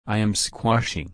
/skwɒʃ/